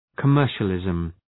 Shkrimi fonetik{kə’mɜ:rʃə,lızm}
commercialism.mp3